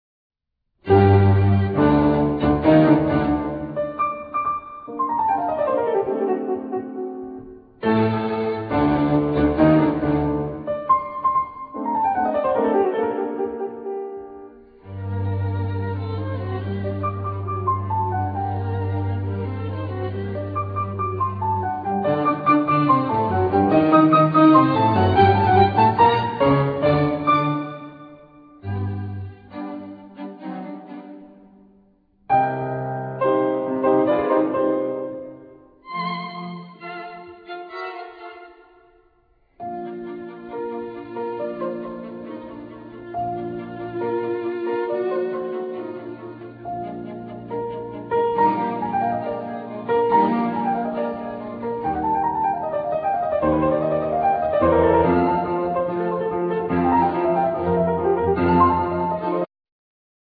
Piano
1st Violin
2nd Violin
Cello
Viola